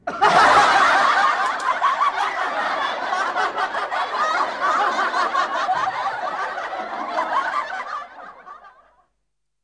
SFX笑二音效下载
SFX音效